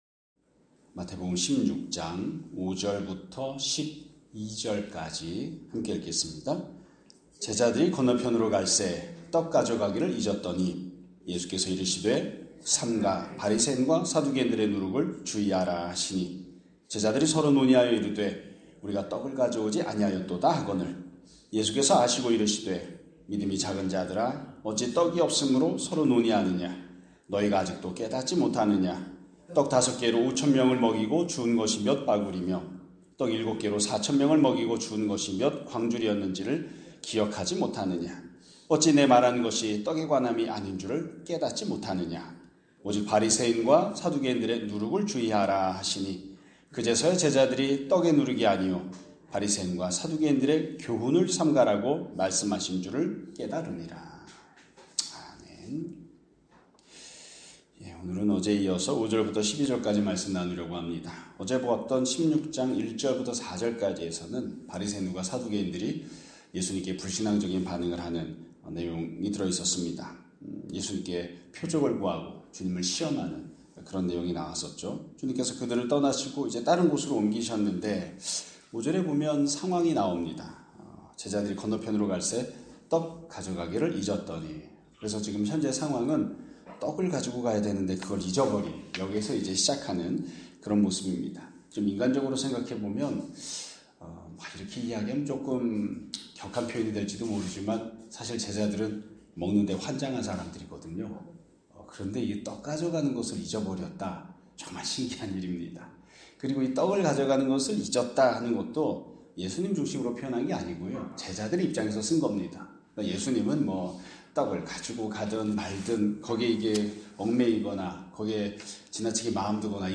2025년 11월 14일 (금요일) <아침예배> 설교입니다.